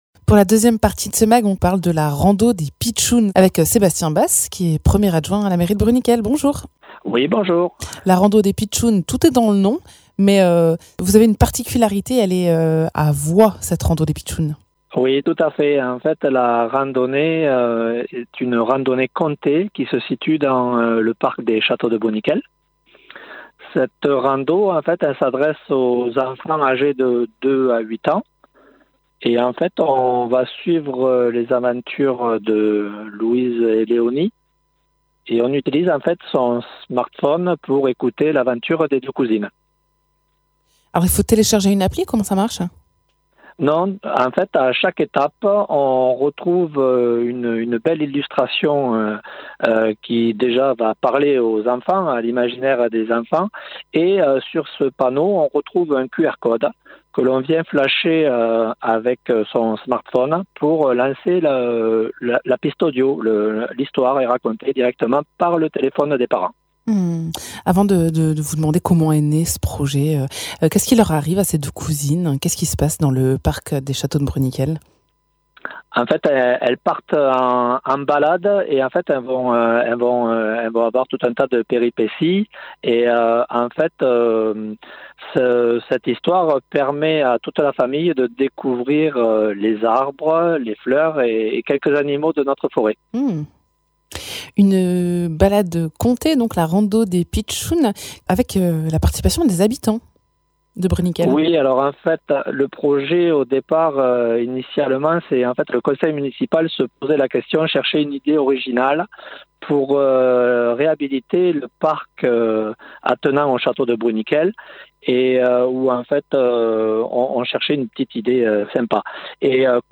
Interviews
Invité(s) : Sébastien Basse, adjoint à la mairie de Bruniquel